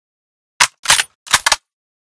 misfire.ogg